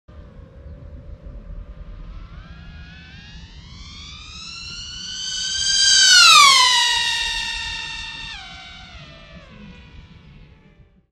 Tiếng FPV Drone bay ngang qua tốc độ cao
Thể loại: Tiếng đồ công nghệ
Description: Hiệu ứng âm thanh sống động này ghi lại tiếng rít mạnh mẽ của máy bay không người lái FPV lướt nhanh, mang đến cảm giác tốc độ cao và công nghệ đỉnh cao. Âm thanh tiếng bay chân thực, sắc nét, lý tưởng để chỉnh sửa video, lồng tiếng hoặc thêm hiệu ứng cho các cảnh hành động, công nghệ.
tieng-fpv-drone-bay-ngang-qua-toc-do-cao-www_tiengdong_com.mp3